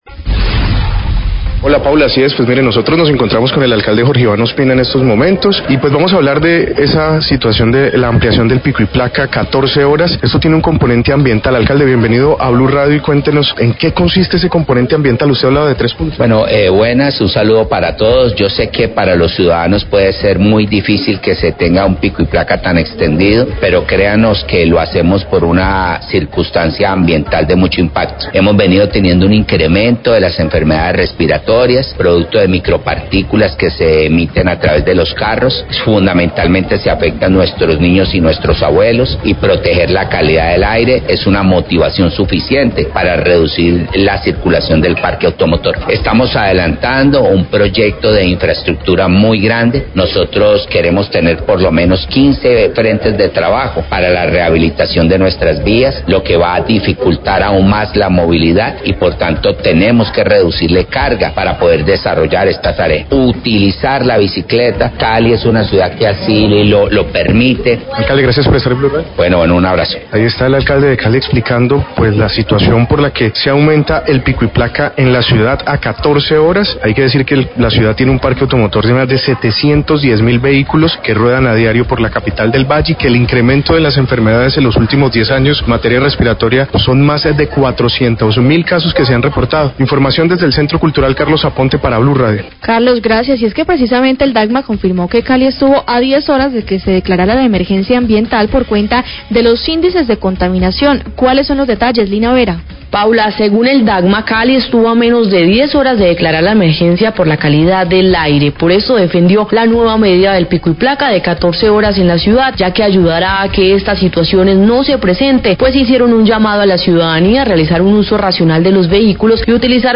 Radio
Alcalde de Cali, Jorge Iván Ospina, habla del componente ambiental que motivó la ampliación del horario de pico y placa en la ciudad. La directora del Dagma, Francy Restrepo, confirmó que Cali estuvo a 10 horas de declarar la emergencia por calidad del aire.